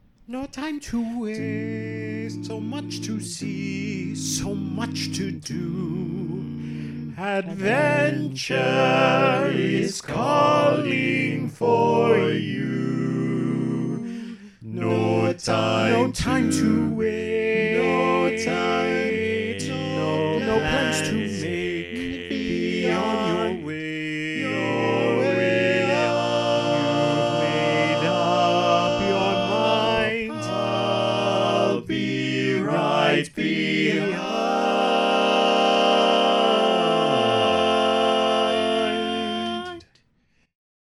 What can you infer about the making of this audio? All Parts mix: